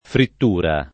[ fritt 2 ra ]